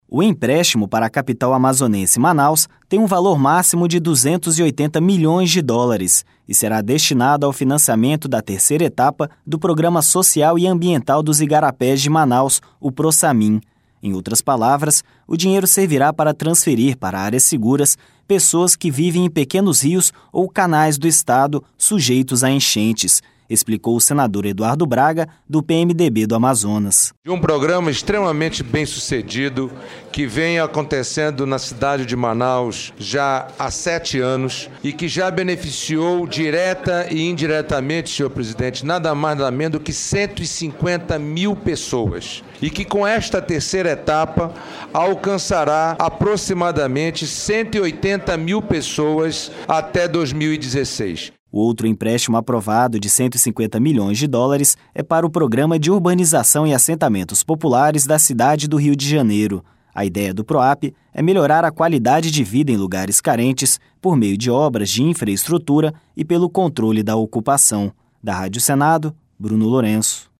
Em outras palavras, o dinheiro servirá para transferir para áreas seguras pessoas que vivem em pequenos rios ou canais do estado sujeitos a enchentes, explicou o senador Eduardo Braga, do PMDB do Amazonas.